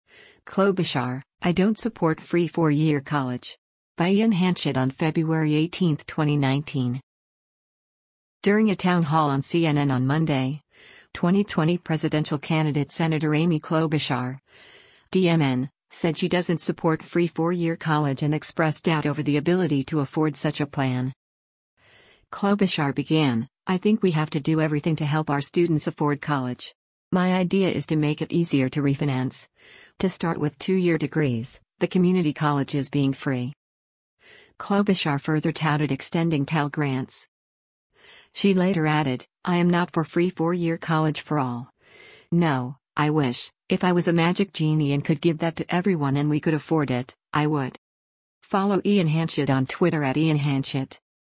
During a town hall on CNN on Monday, 2020 presidential candidate Senator Amy Klobuchar (D-MN) said she doesn’t support free four-year college and expressed doubt over the ability to afford such a plan.